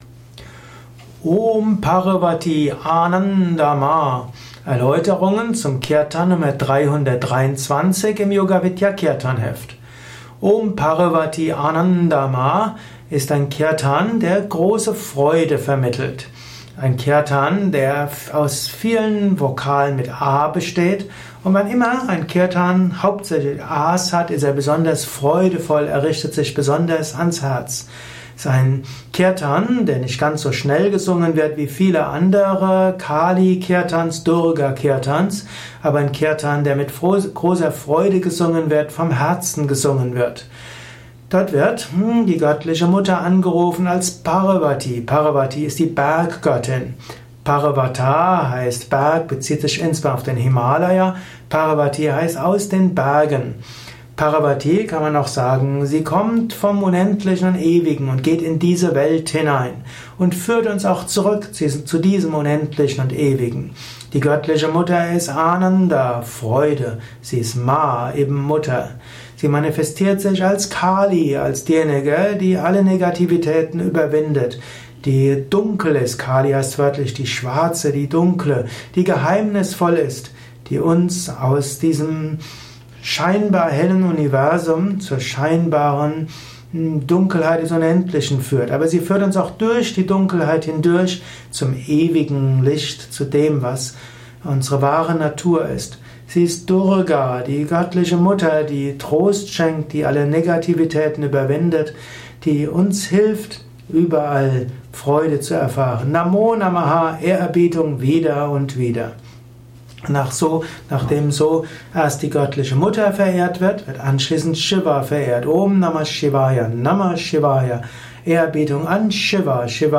Yoga Vidya Kirtanheft , Tonspur eines Kirtan Lehrvideos.